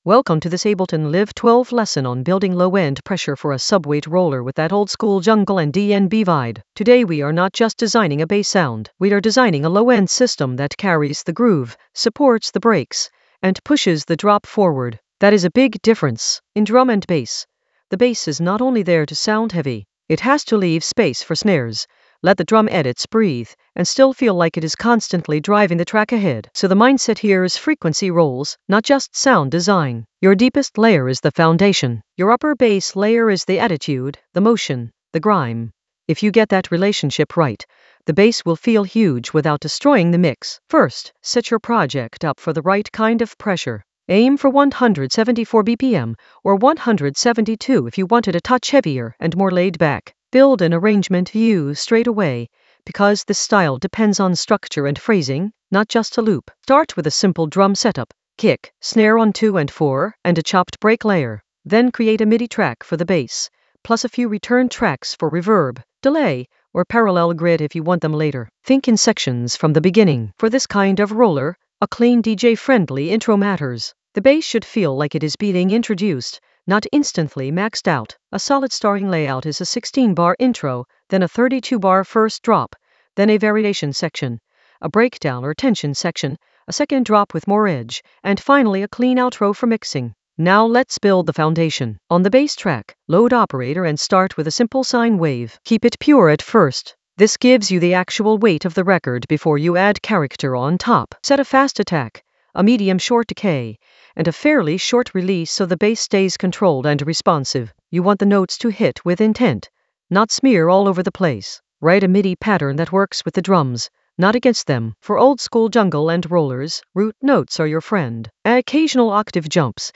An AI-generated intermediate Ableton lesson focused on Low-End Pressure a subweight roller: design and arrange in Ableton Live 12 for jungle oldskool DnB vibes in the FX area of drum and bass production.
Narrated lesson audio
The voice track includes the tutorial plus extra teacher commentary.